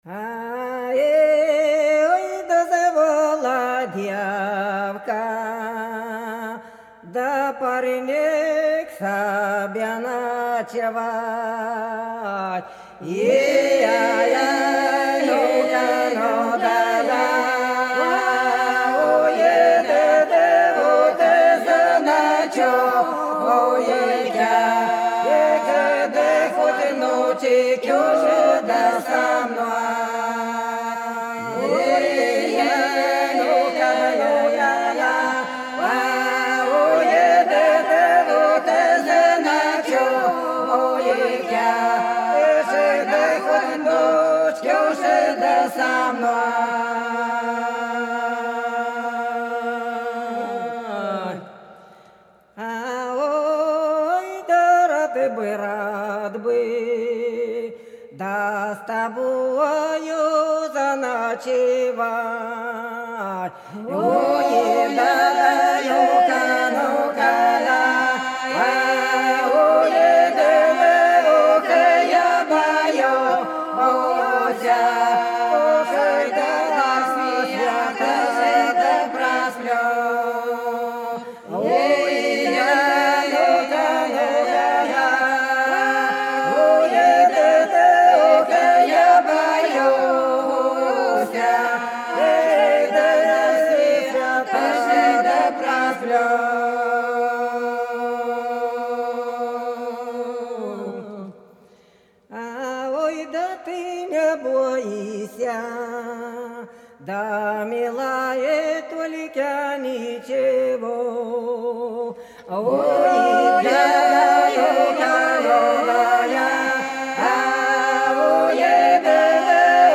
Белгородские поля (Поют народные исполнители села Прудки Красногвардейского района Белгородской области) Звала девка к себе парня ночевать - протяжная